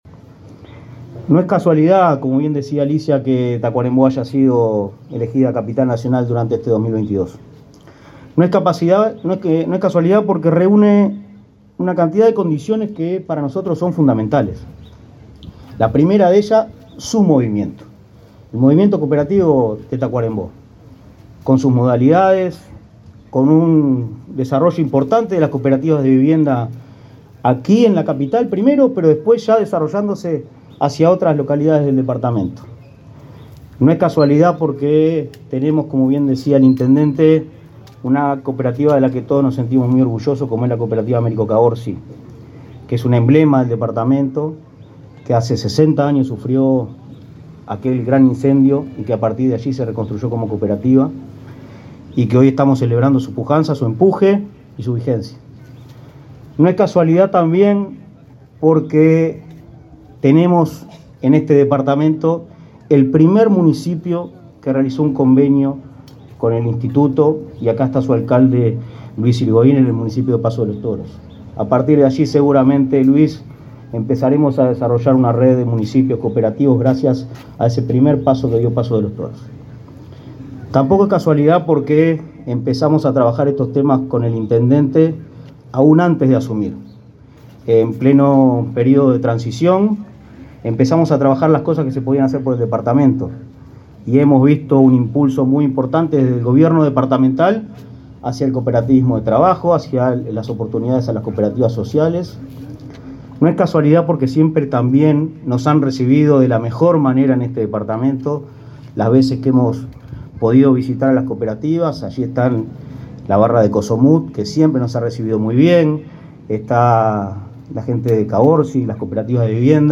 Palabras de autoridades en acto por el Día Internacional del Cooperativismo
El titular del Instituto Nacional del Cooperativismo (Inacoop), Martín Fernández, y el secretario de Presidencia de la República, Álvaro Delgado,